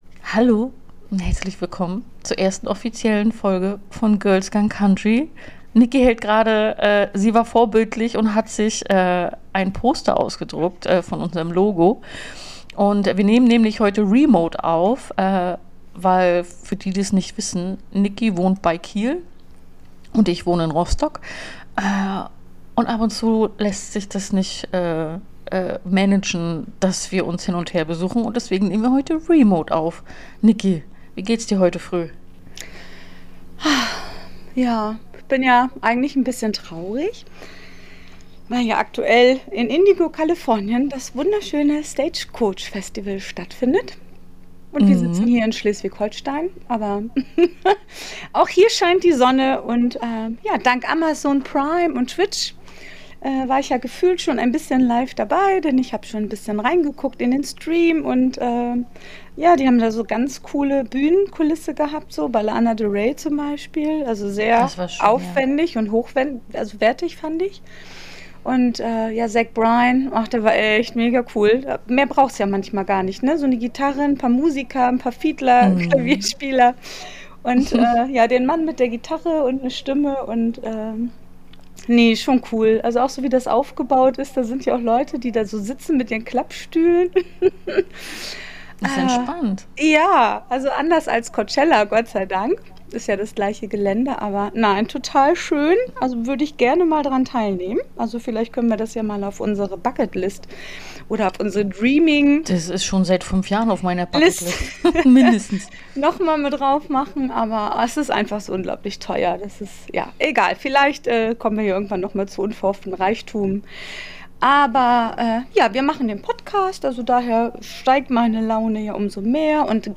In der neuen Folge von Girls Gone Country wird ordentlich geschnackt, gelacht und ein bisschen geschwärmt.